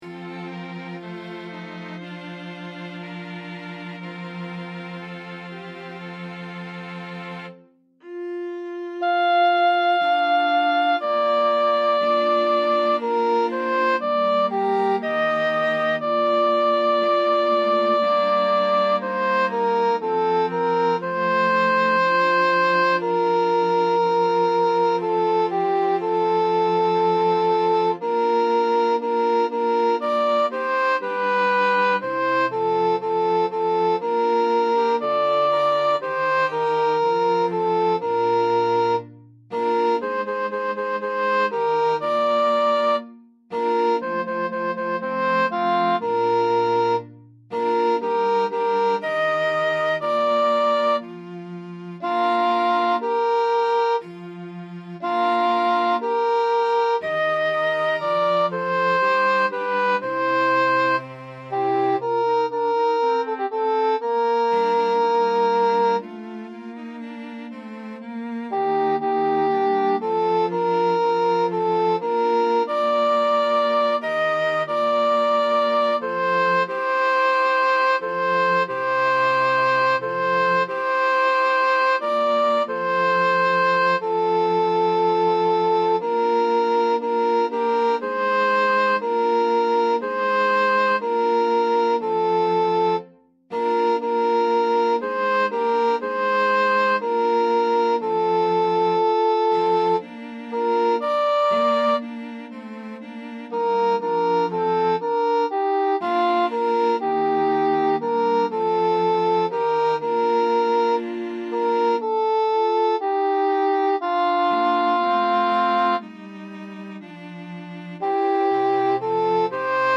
GeselligkeitChormusikSopran
Audite nova Orlando di Lasso Sopran als Mp3 Audite nova Orlando di Lasso Sopran als Mp3 Zuerst die Takte 7 bis 10 als Intonation.
audite-nova-orlando-di-lasso-einstudierung-sopran.mp3